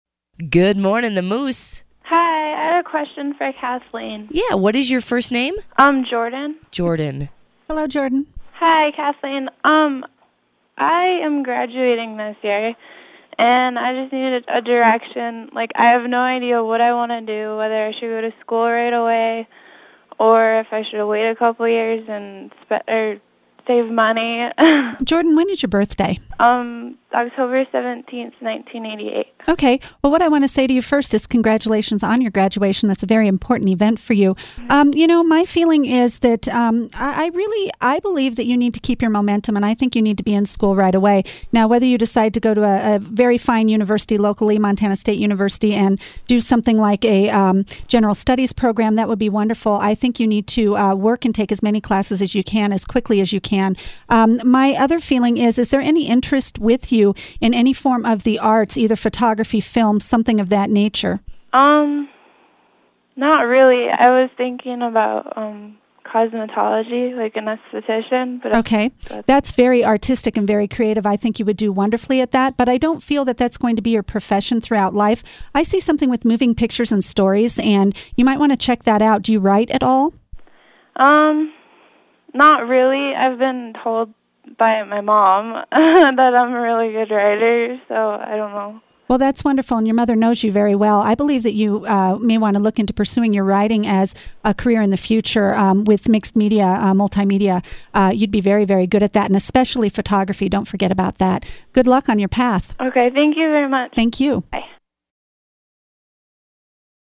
Live in the studio on KMMS 95.1 FM “The Moose” in Bozeman, Montana